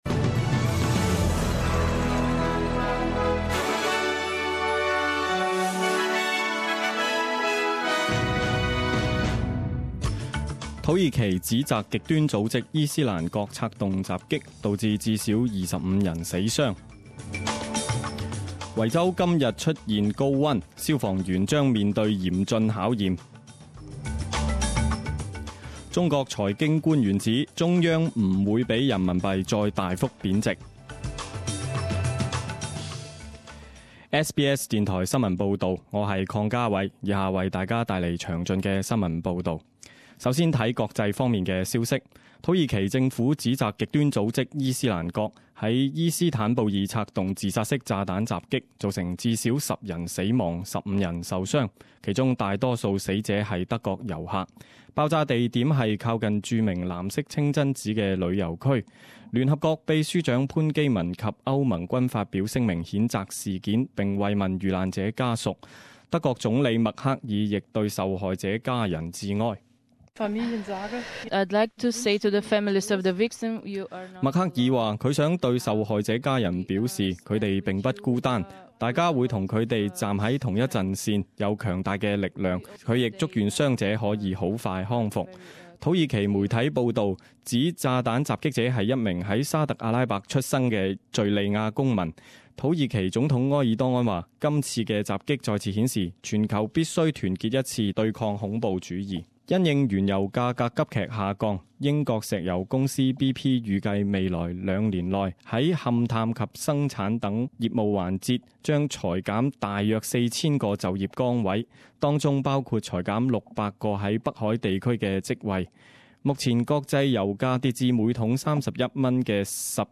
十点钟新闻报导 （一月十三日）